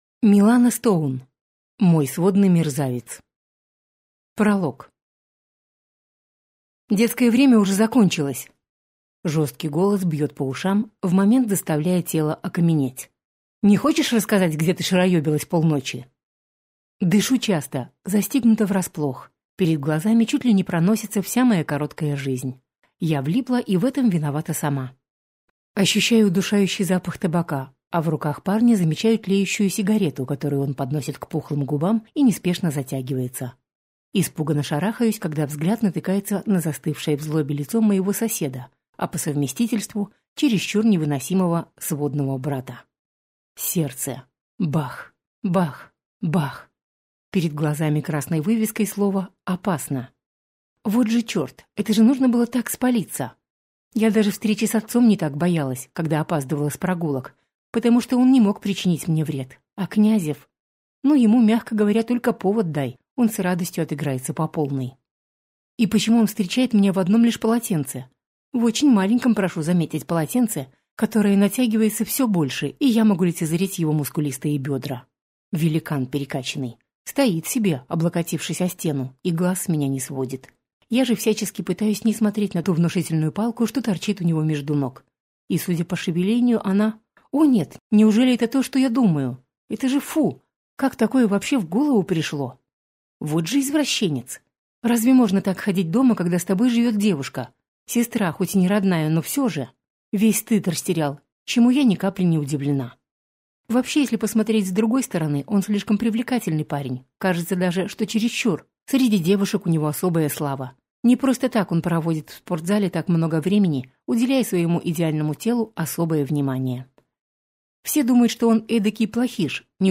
Аудиокнига Мой сводный мерзавец | Библиотека аудиокниг